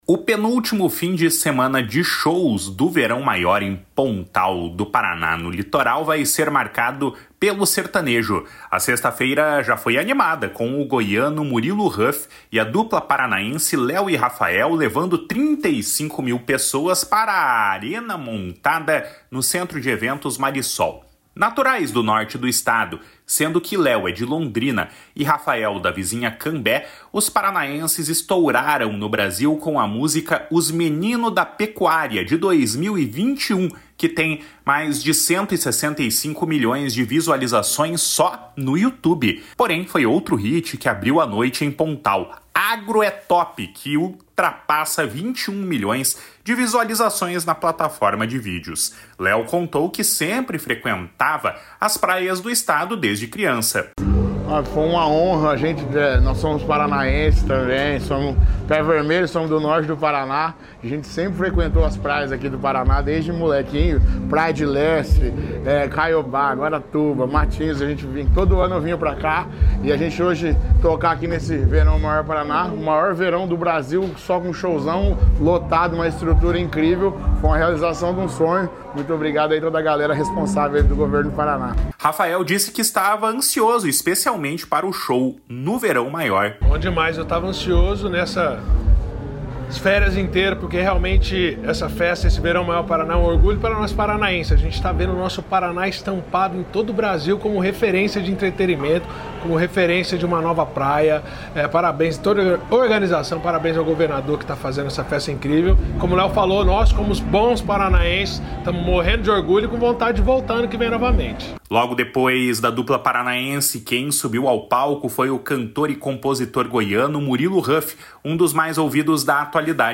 Raphael disse que estava ansioso especialmente para o show no Verão Maior.
O cantor se disse impressionado com os shows do festival.
O prefeito de Pontal do Paraná, Rudão Gimenes, acompanhou os shows e destacou como as atrações semanais estão mantendo os veranistas no Litoral ao longo da temporada.